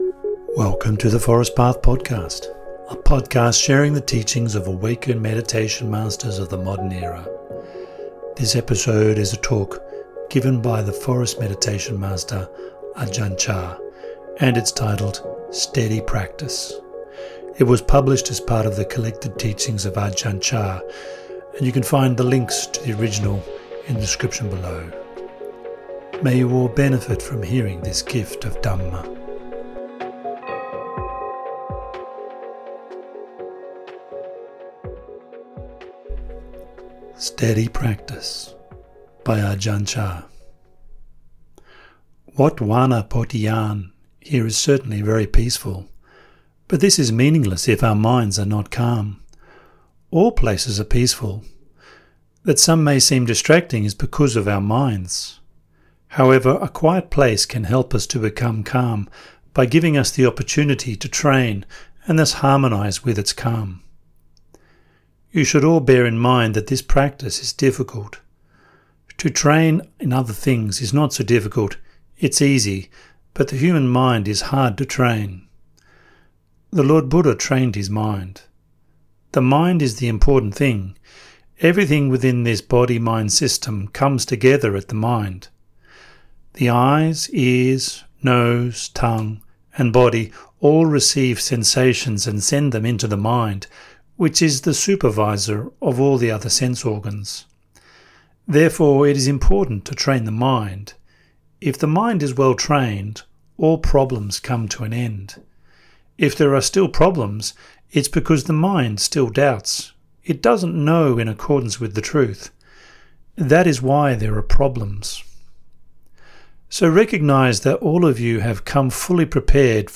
Narrated translations of meditation masters of the forest tradition of Theravada Buddhism.